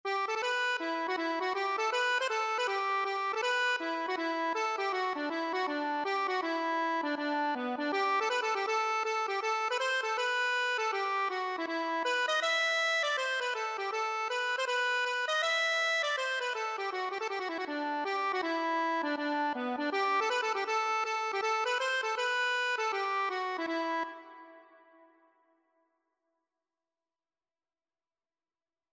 Traditional Trad. I Dreamt of My Love (Irish Folk Song) Accordion version
Traditional Music of unknown author.
E minor (Sounding Pitch) (View more E minor Music for Accordion )
4/4 (View more 4/4 Music)
B4-E6
Instrument:
Traditional (View more Traditional Accordion Music)